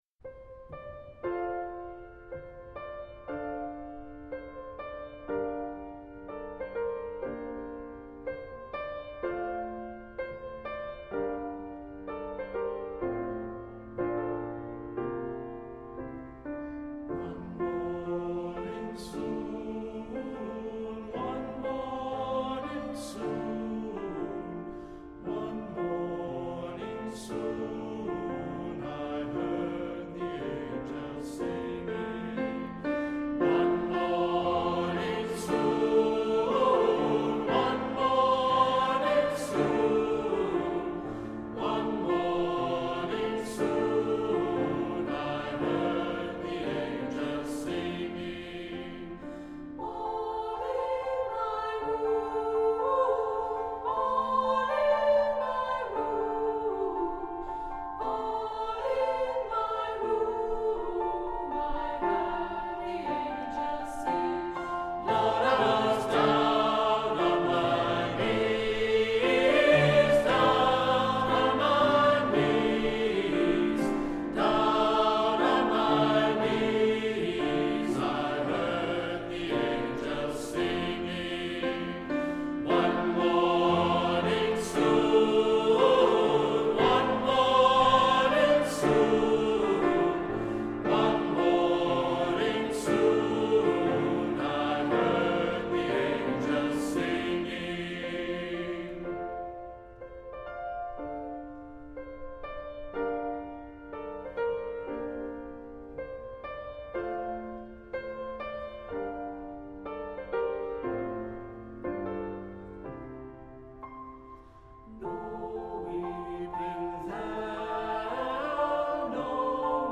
Voicing: Unison; Two-part equal